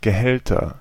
Ääntäminen
Ääntäminen Tuntematon aksentti: IPA: /ɡəˈhɛltɐ/ Haettu sana löytyi näillä lähdekielillä: saksa Käännöksiä ei löytynyt valitulle kohdekielelle.